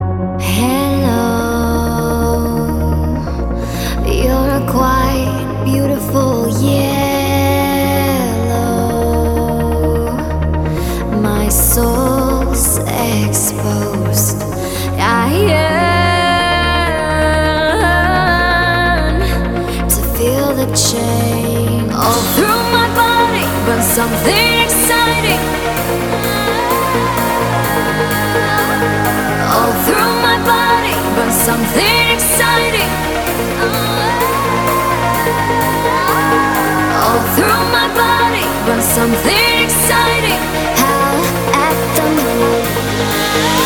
EDM
спокойные
progressive house
красивый женский голос
нежные
Жанр: Progressive House